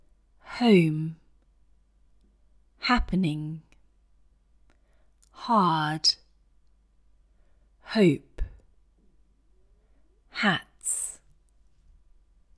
While there are some English words that have a silent h, such as hour or honest, or small “h” words in fast connected speech, most other words have pronounced /h/ sounds.